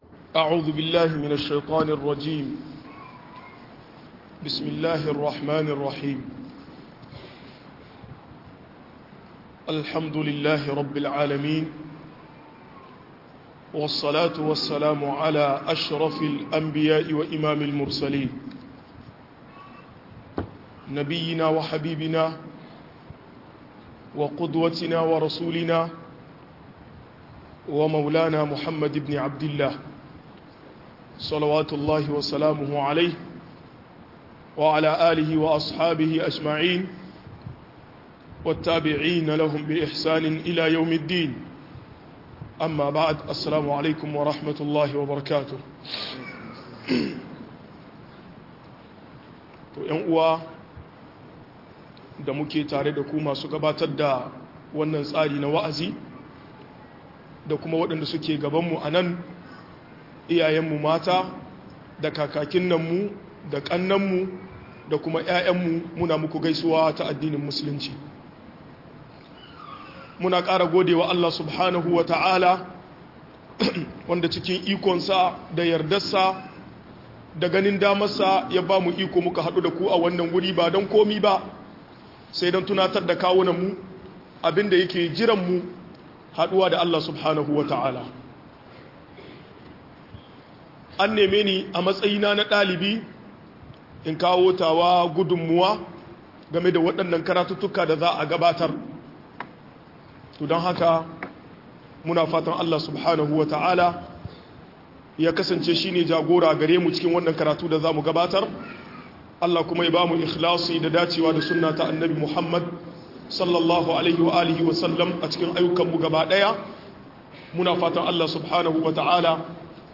Kalubalen da mata musulmai ke fuskanta - MUHADARA